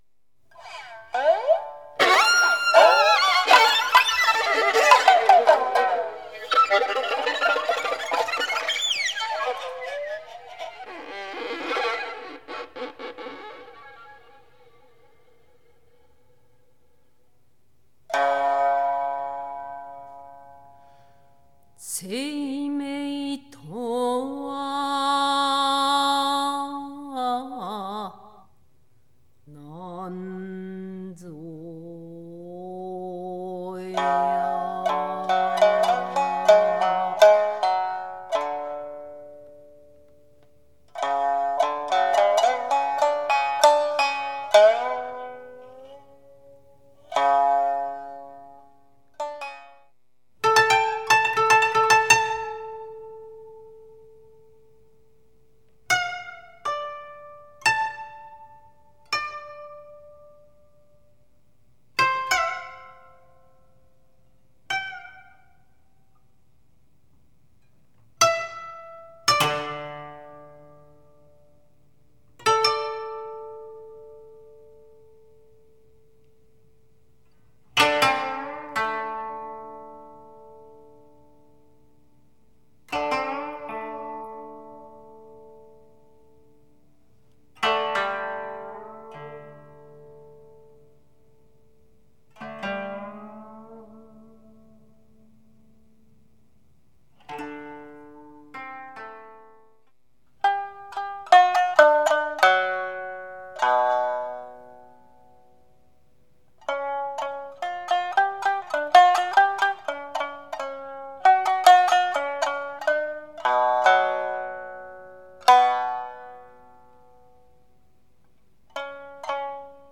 koto, shamisen and 17 stringed koto
sax, vocal
piano
violin